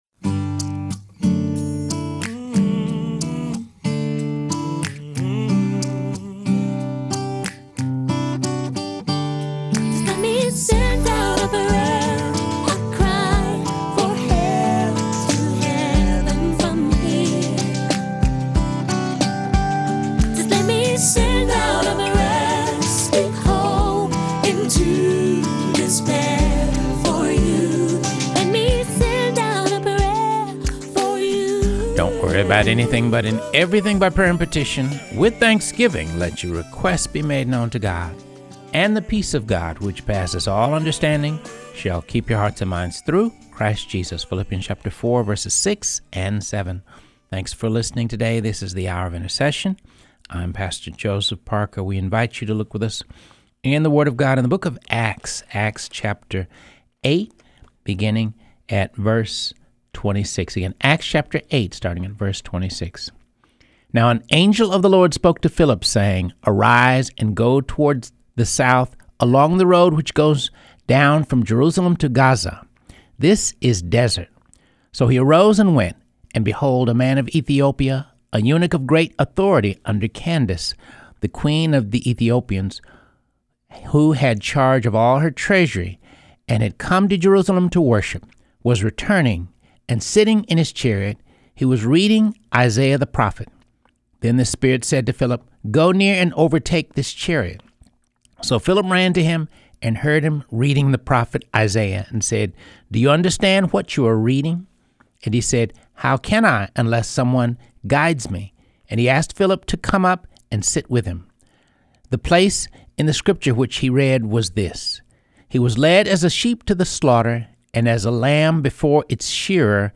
Teaching: The Ministry and Power of the Holy Spirit